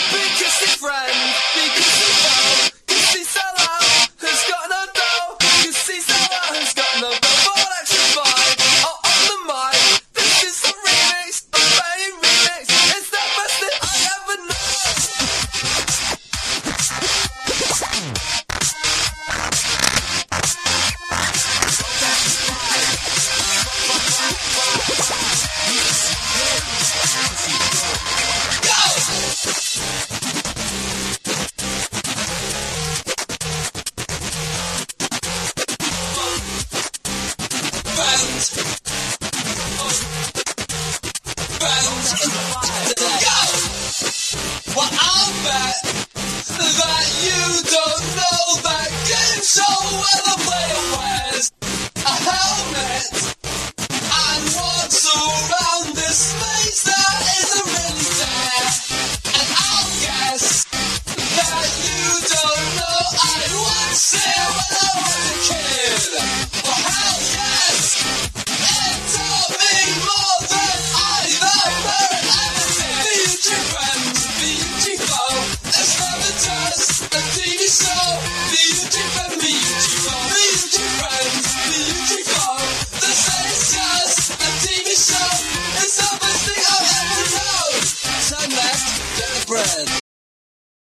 1. 00S ROCK >
INDIE DANCE